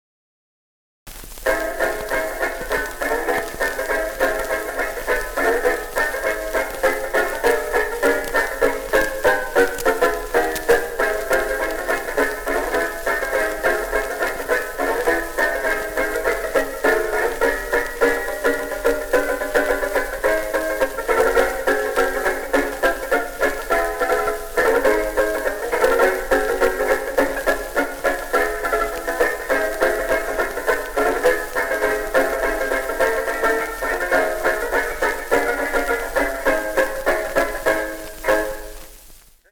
tenor banjo